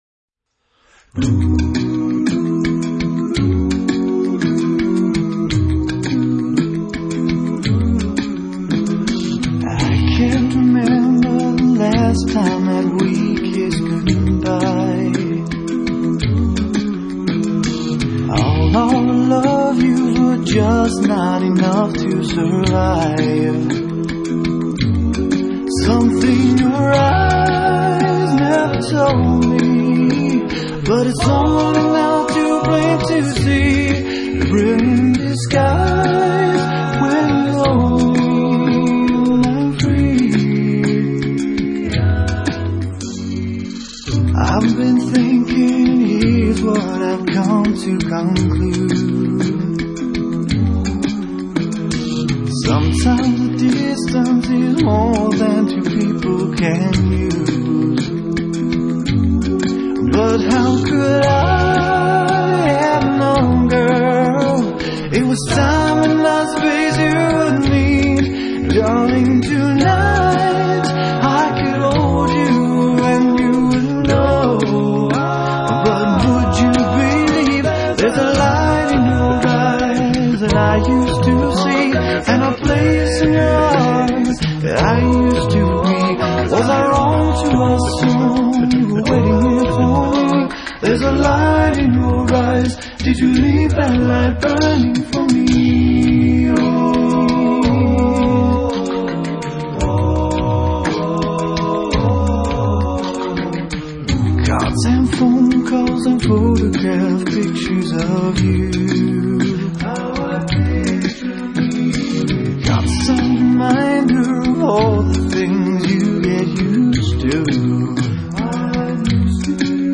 the best six-man a cappella group in the West!
No Instruments Were Used In The Making Of This Music!